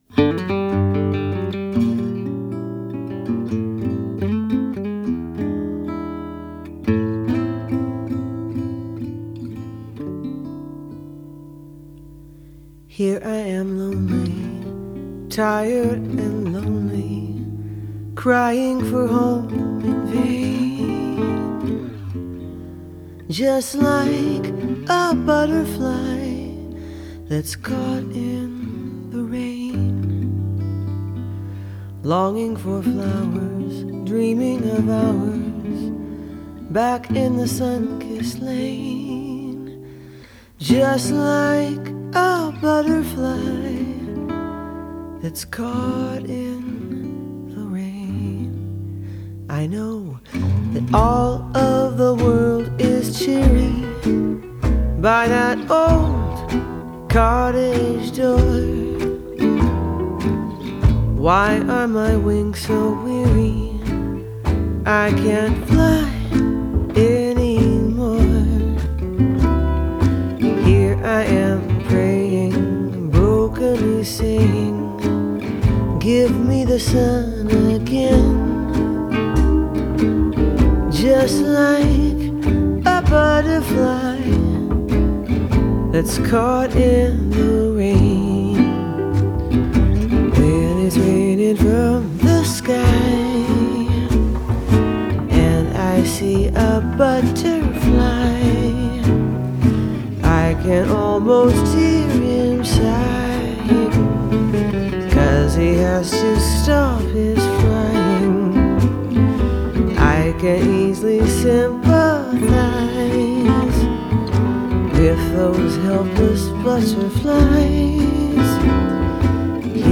Genre: Jazz, Vocal Jazz